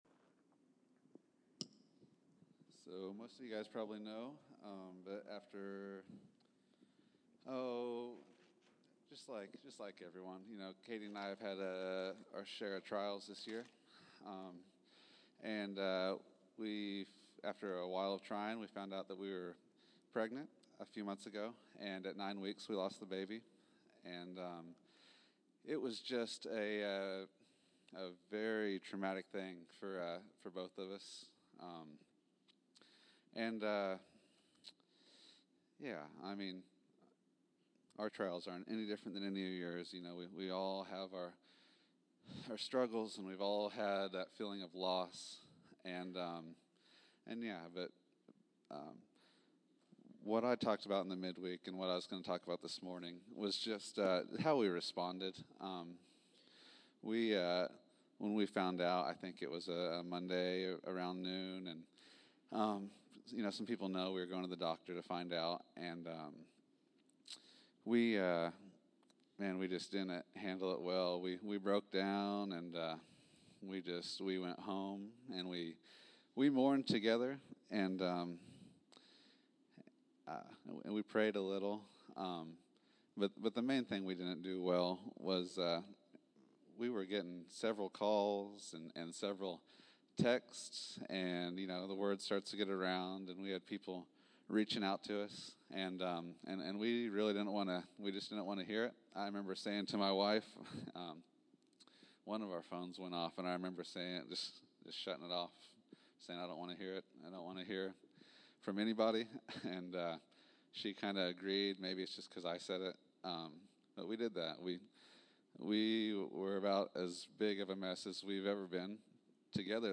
May 03, 2015      Category: Testimonies      |      Location: El Dorado